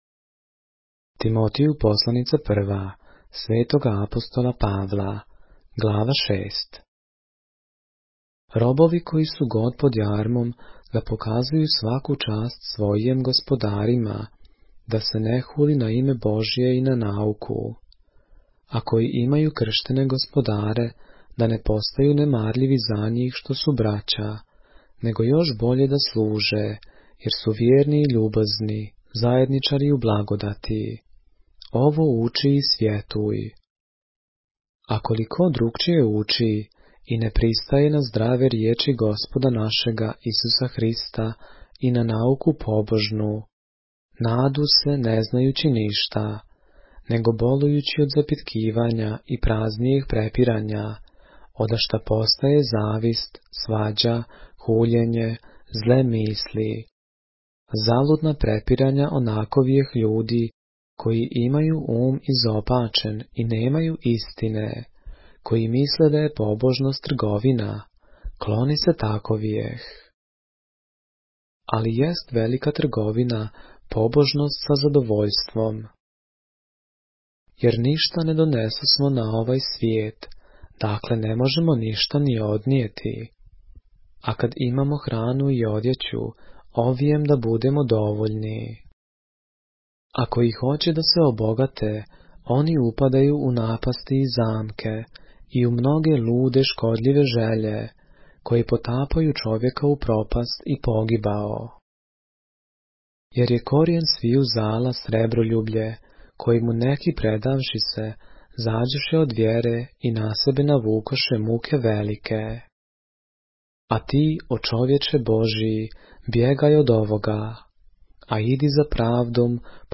поглавље српске Библије - са аудио нарације - 1 Timothy, chapter 6 of the Holy Bible in the Serbian language